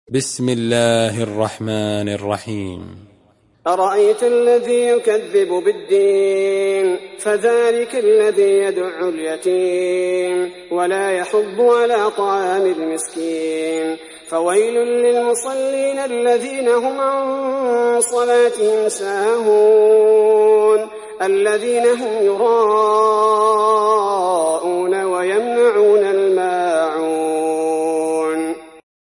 تحميل سورة الماعون mp3 بصوت عبد الباري الثبيتي برواية حفص عن عاصم, تحميل استماع القرآن الكريم على الجوال mp3 كاملا بروابط مباشرة وسريعة